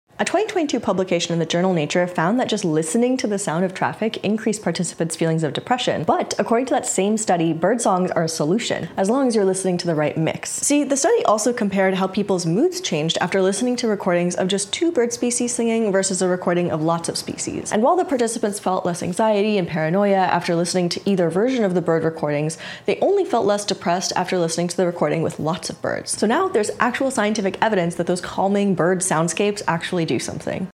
There may be some hidden scientific validity to listening to relaxing soundscape tracks... If they have enough birds in the choir.